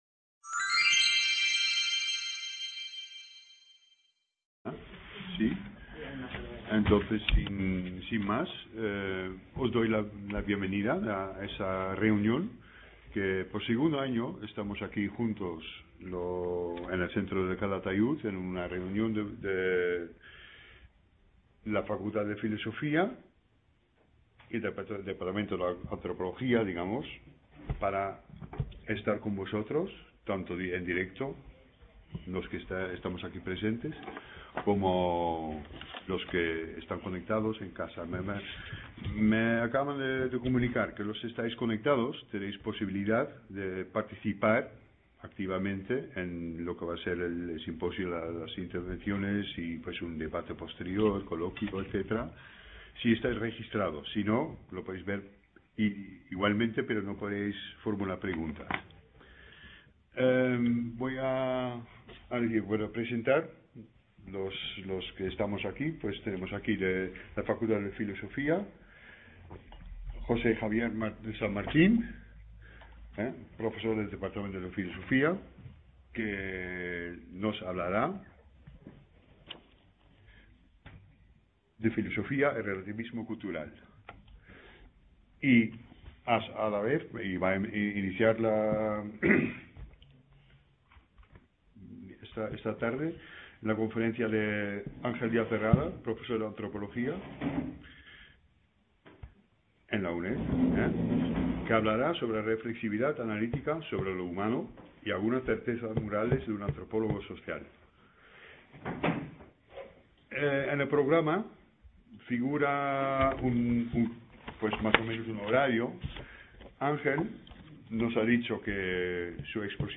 Perspectivas de la antropología filosófica y cultural, parte 1 Description El Seminario ofrece la doble perspectiva, científica y filosófica, de la Antropología.